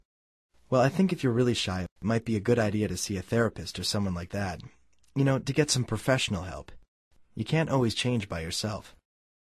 Third speaker
Tercer orador